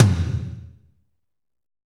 TOM F RLM1EL.wav